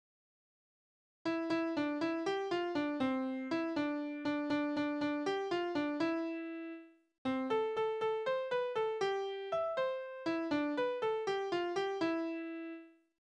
Naturlieder: Ich habe den Frühling gesehen
Tonart: C-Dur
Taktart: 6/8
Tonumfang: große Dezime
Besetzung: vokal